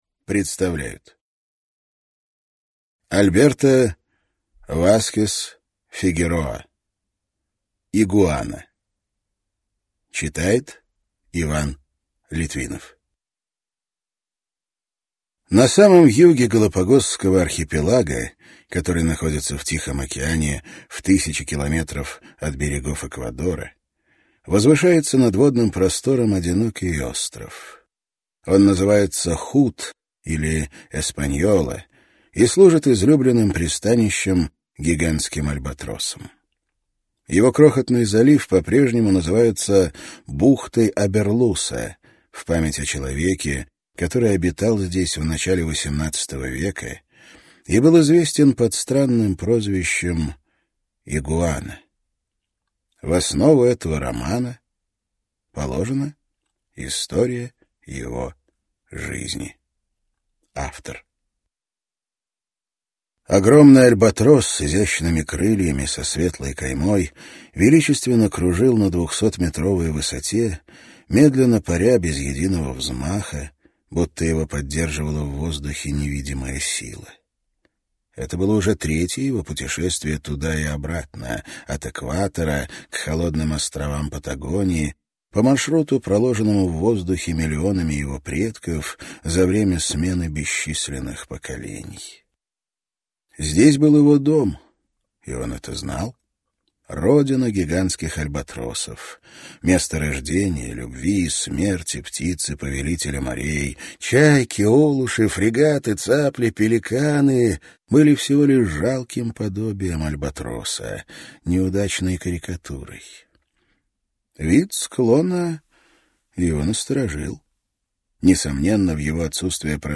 Аудиокнига Игуана | Библиотека аудиокниг